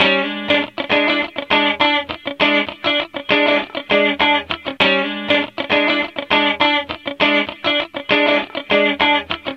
Sons et loops gratuits de guitares rythmiques 100bpm
Guitare rythmique 41